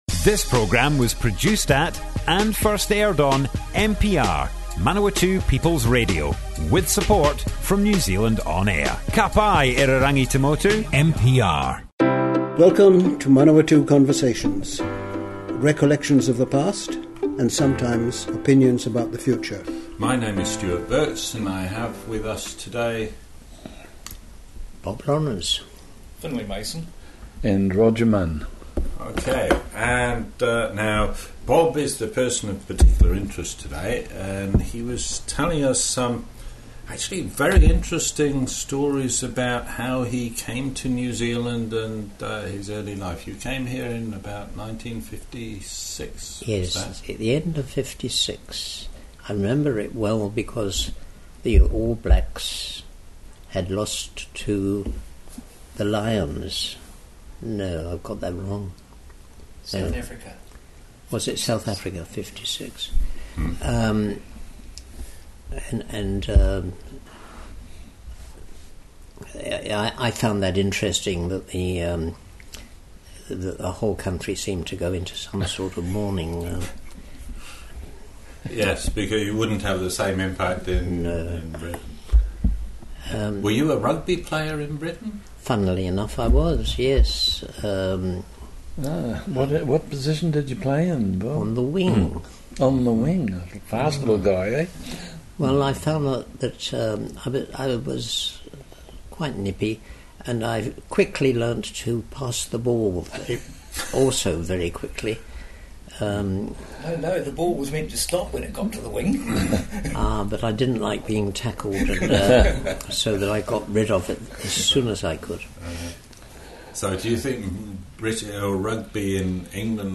Manawatu Conversations More Info → Description Broadcast on Manawatu People's Radio, 31 July 2018.